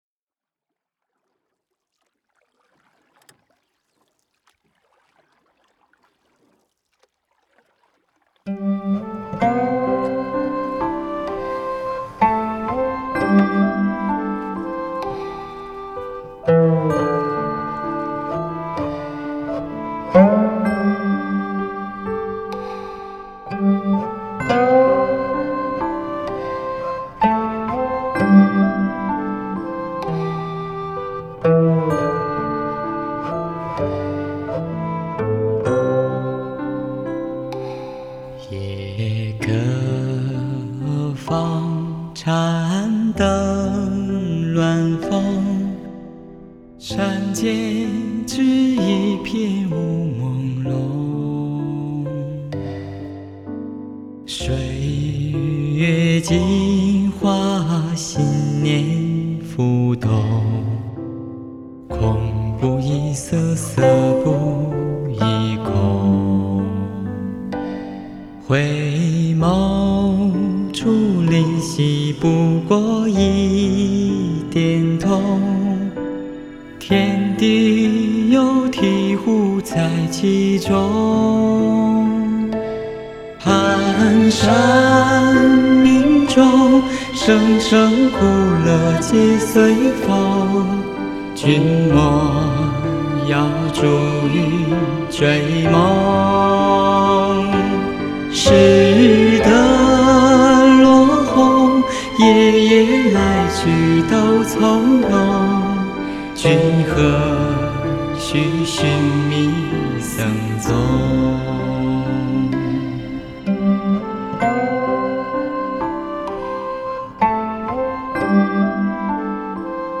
古琴